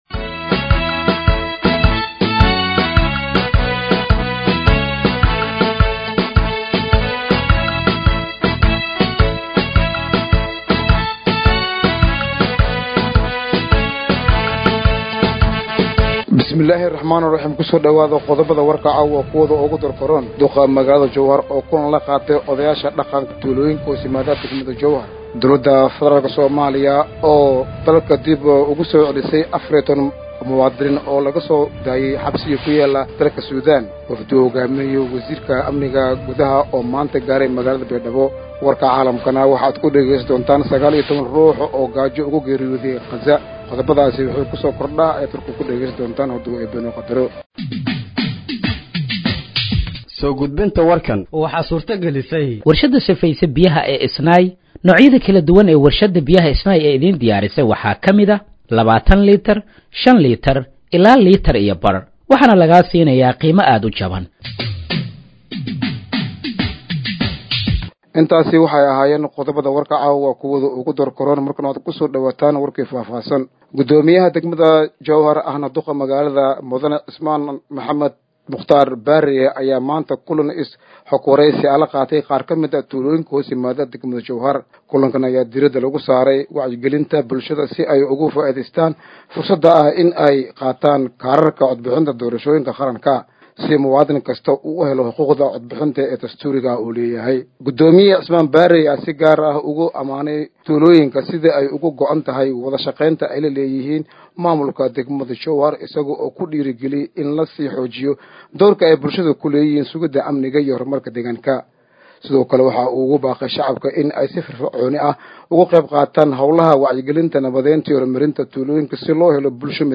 Dhageeyso Warka Habeenimo ee Radiojowhar 21/07/2025